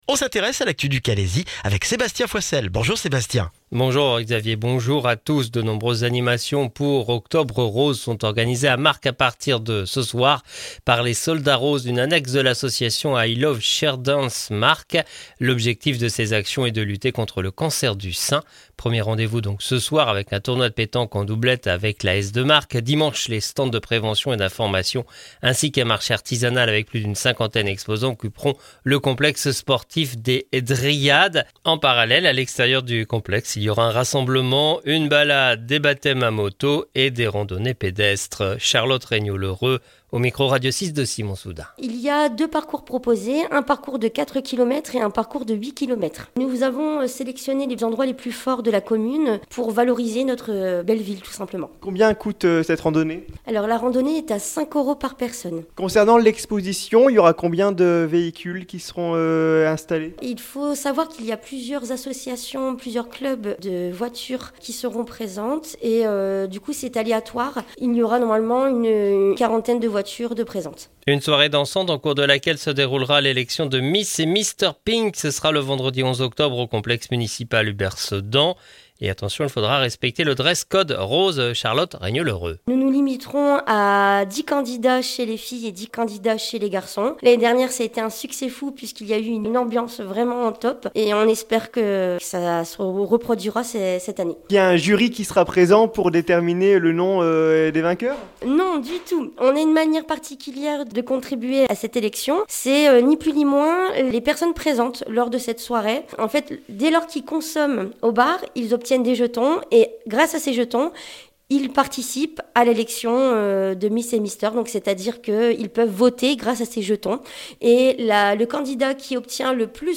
Le journal du vendredi 4 octobre dans le Calaisis